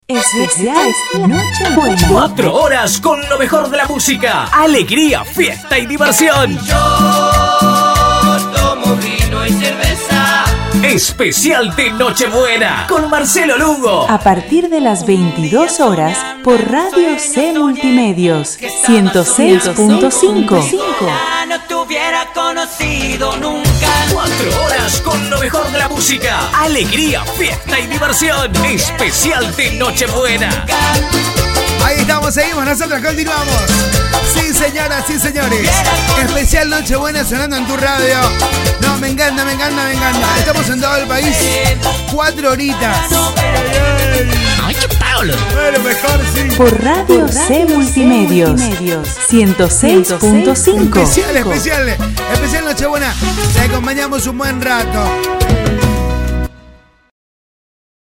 PROMO PROGRAMA ESPECIAL de 22  a 02